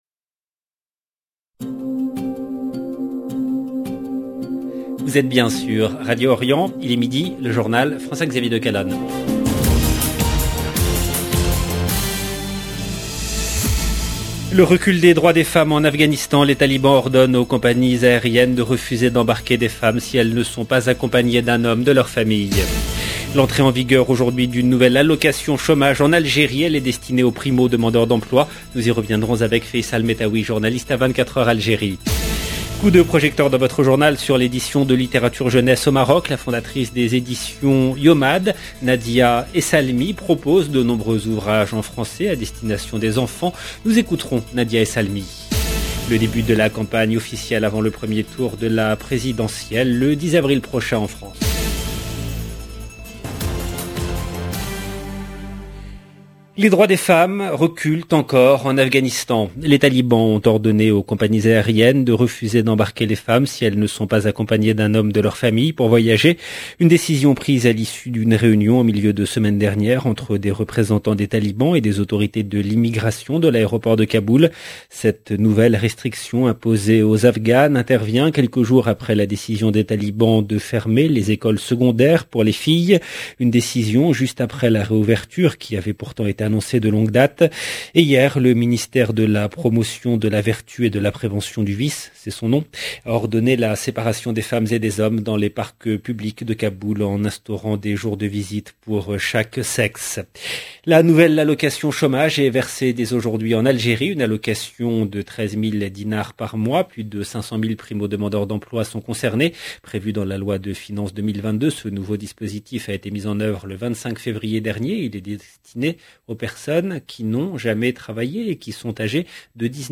LE JOURNAL DE MIDI EN LANGUE FRANCAISE DU 28/03/22